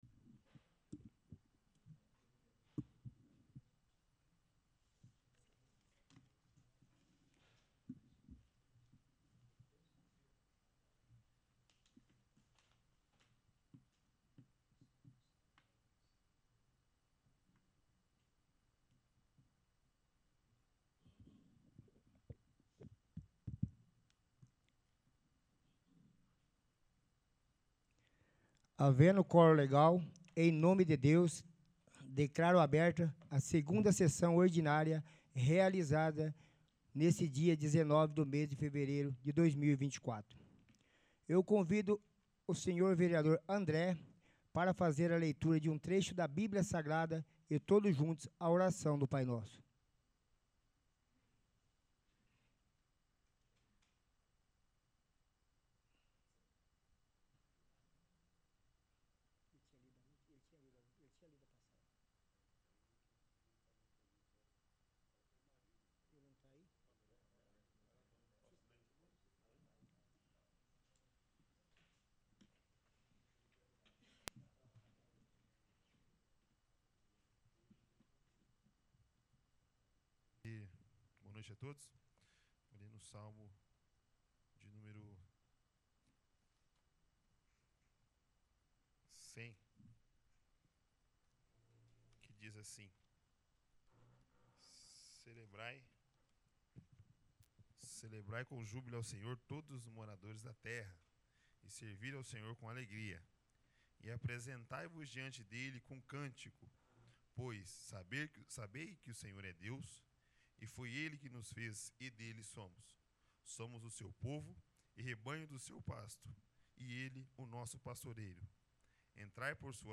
2º. Sessão Ordinária